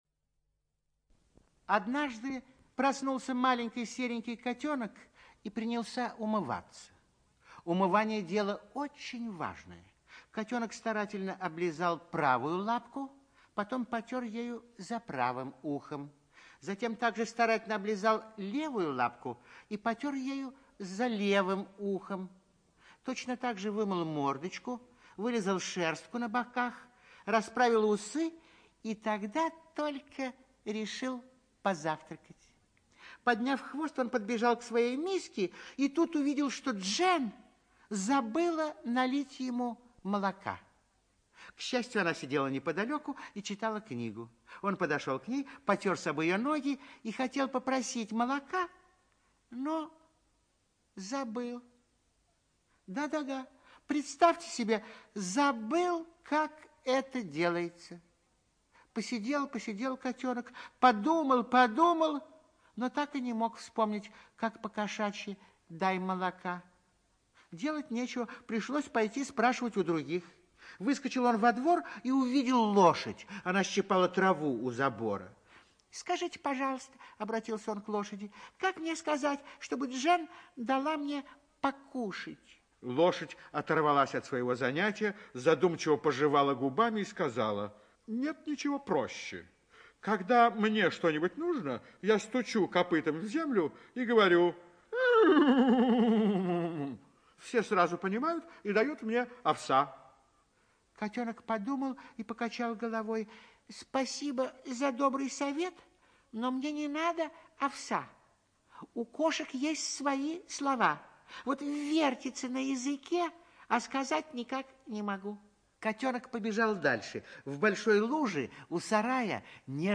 ЧитаетЛитвинов Н.